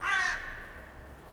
croworraven2.wav